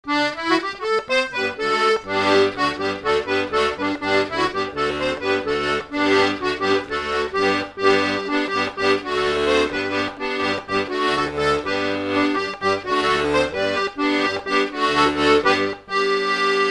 danse-jeu : guimbarde
Pièce musicale inédite